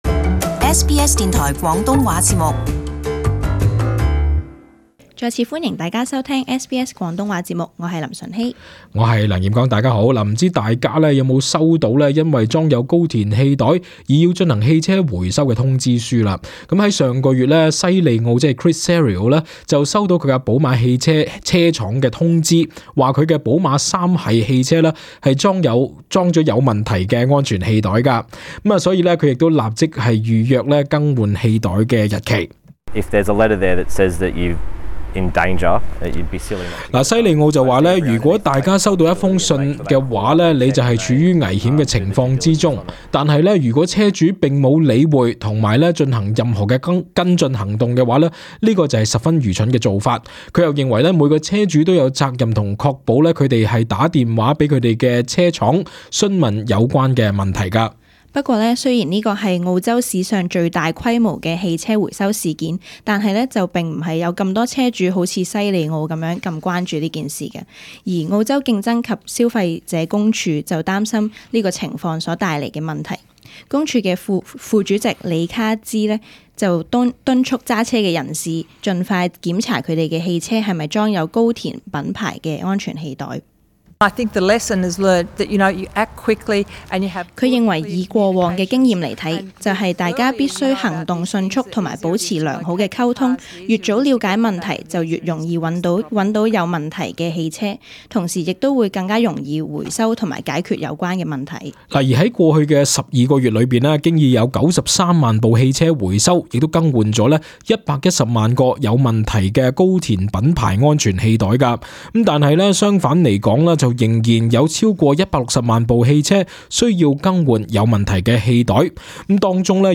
【時事報導】近200萬部汽車仍未更換問題安全氣袋